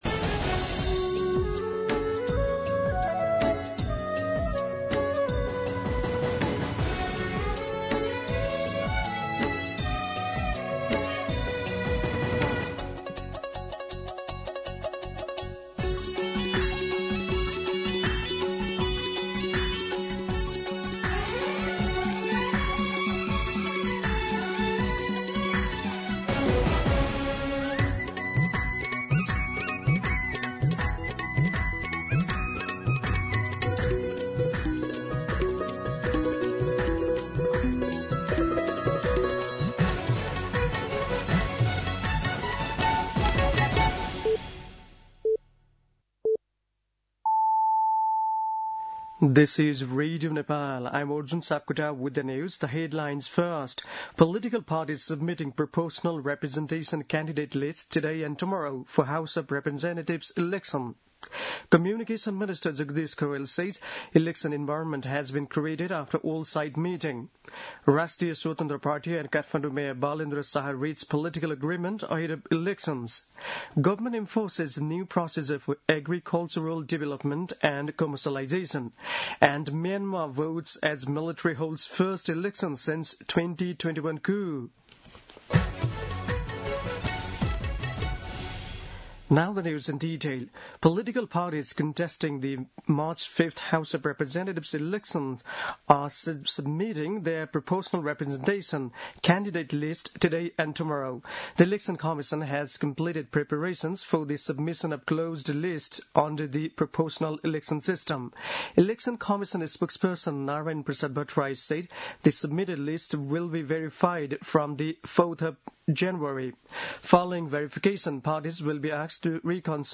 An online outlet of Nepal's national radio broadcaster
दिउँसो २ बजेको अङ्ग्रेजी समाचार : १३ पुष , २०८२
2pm-English-News-13.mp3